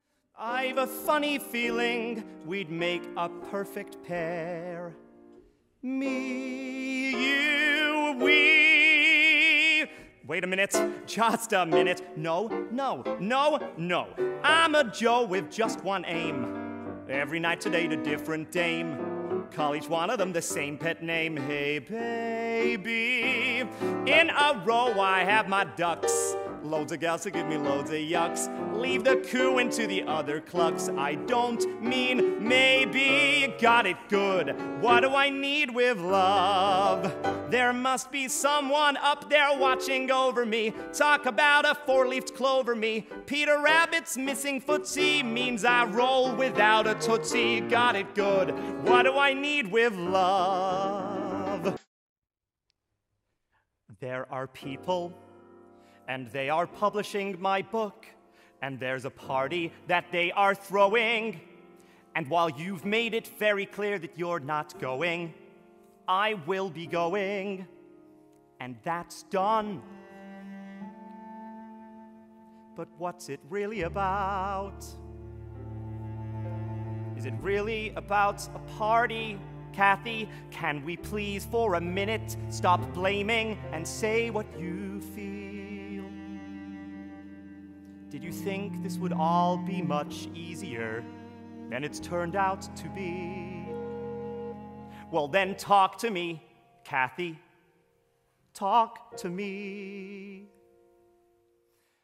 Musical Theatre
Tenor Baritone E2 to G4 Dances